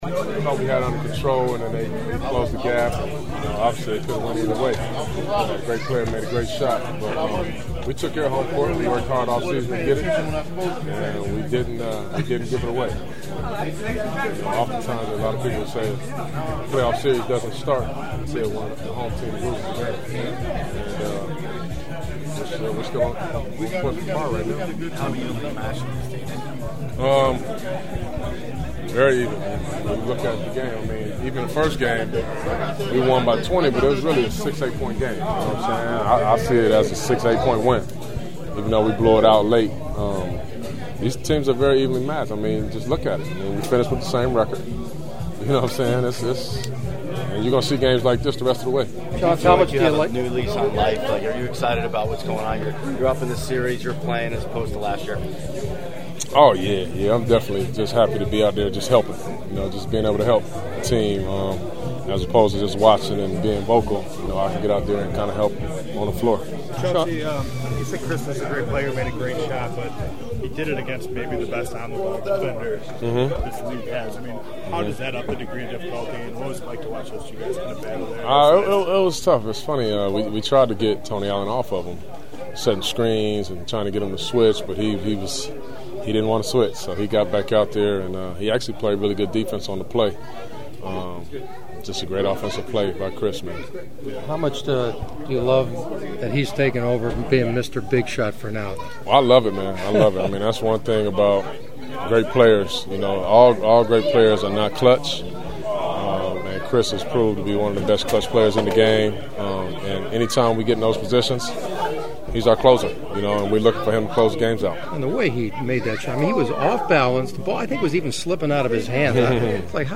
The following are my postgame Clipper interviews along with several preview thoughts for games 3 and 4 in Memphis on Thursday and Saturday (which you can hear of course on KFWB Newstalk 980).